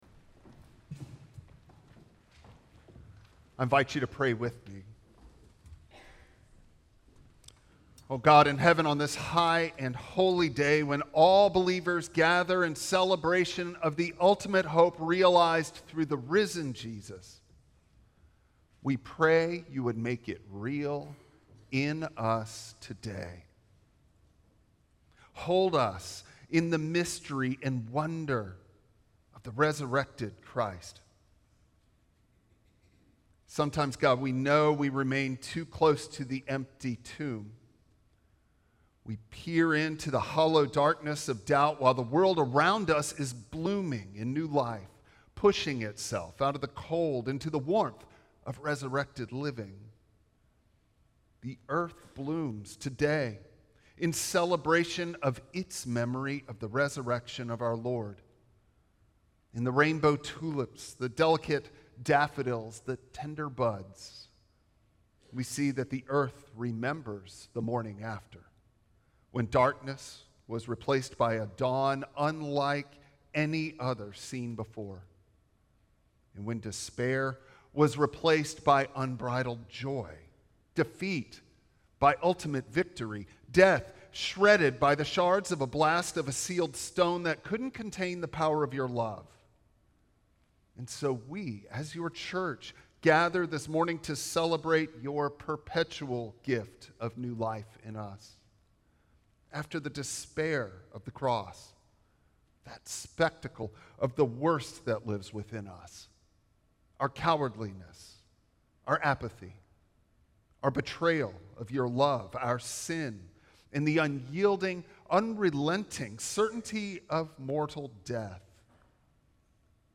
Passage: Romans 6:1-9 Service Type: Traditional Service Bible Text